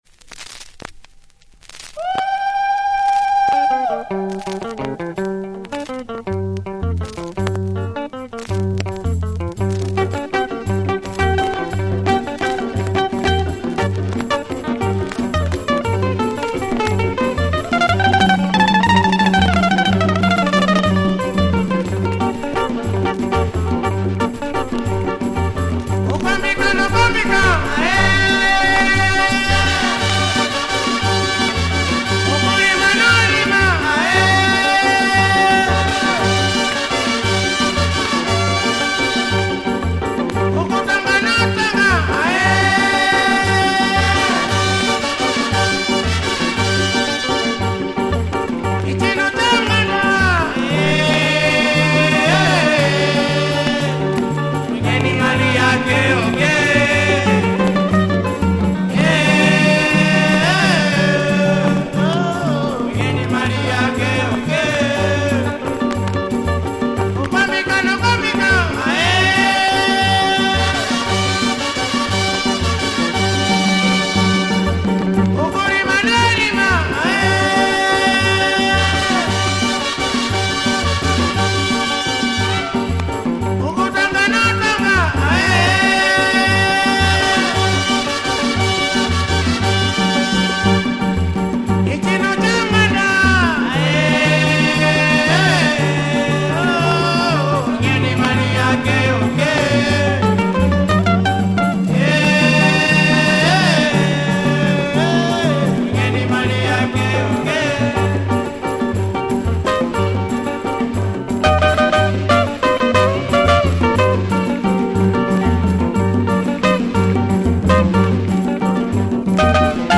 Remarkable guitar work, chanting horns!